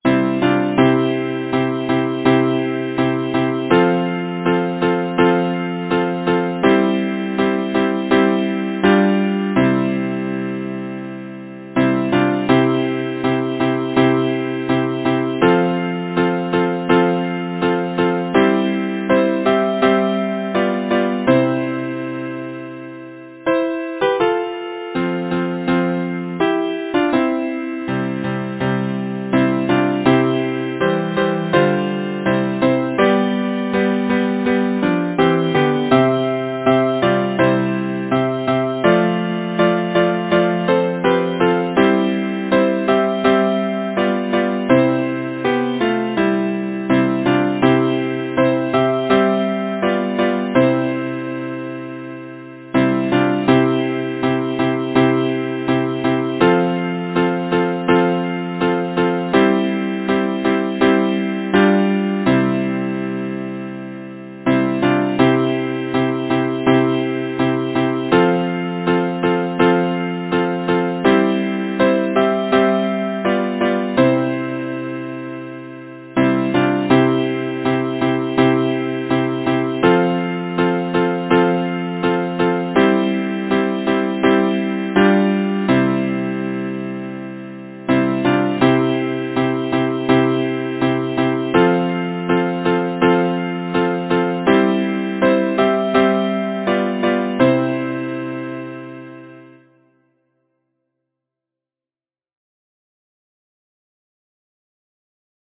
Title: For the sun shineth bright over all Composer: John Harrison Tenney Lyricist: Anzentia Igene Perry Chapman Number of voices: 4vv Voicing: SATB Genre: Secular, Partsong
Language: English Instruments: A cappella